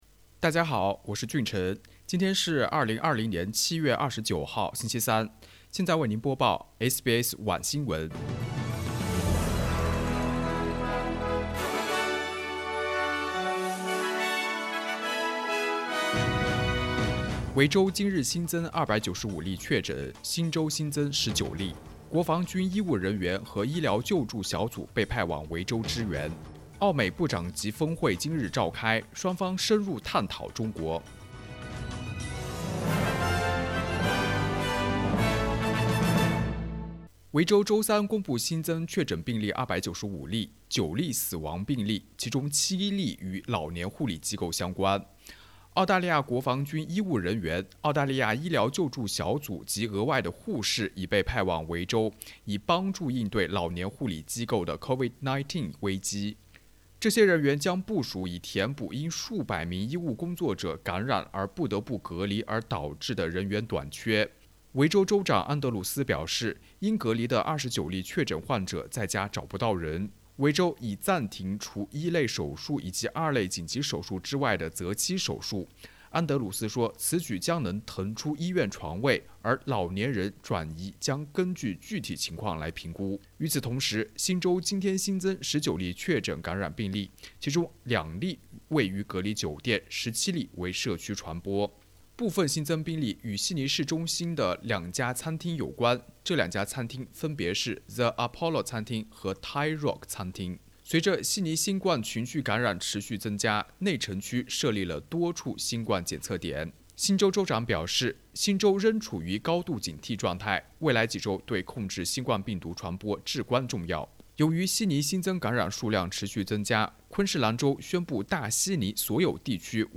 SBS晚新闻（7月29日）
sbs_mandarin_evening_news_0729.mp3